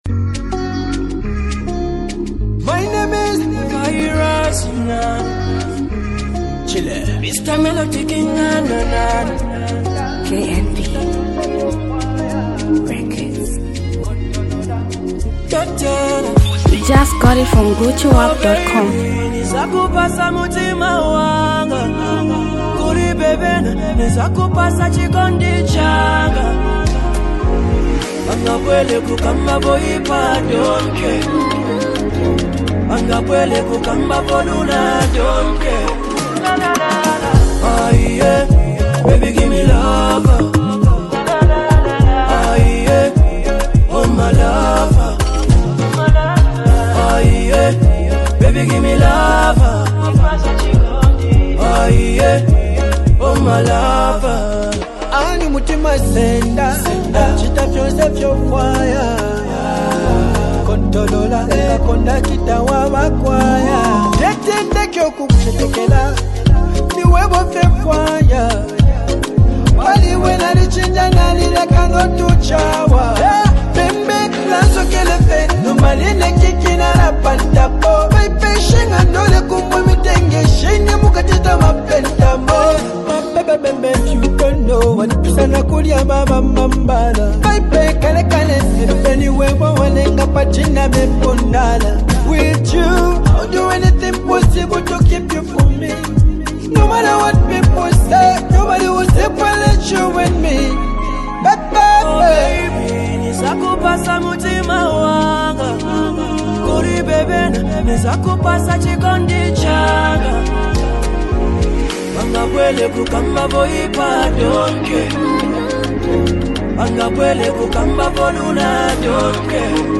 Zambian Mp3 Music
summerly hit jam